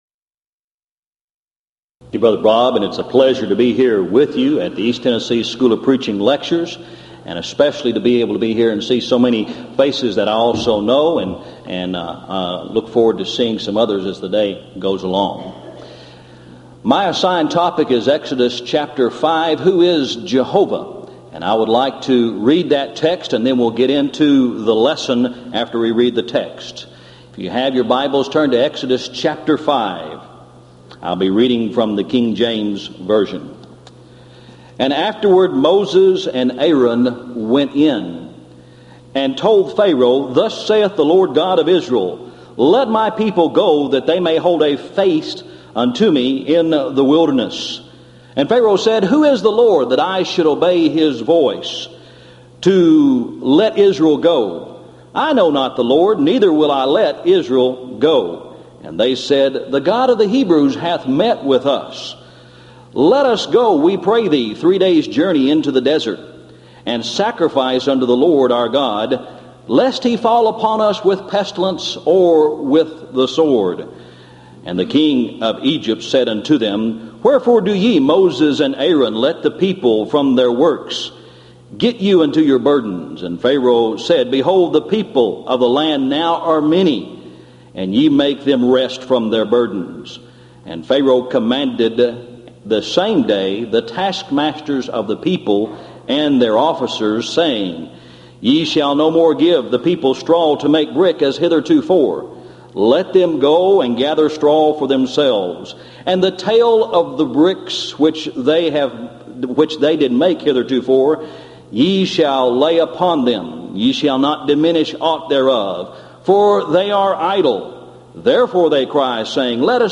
Event: 1997 East Tennessee School of Preaching Lectures Theme/Title: Studies In The Book of Exodus
lecture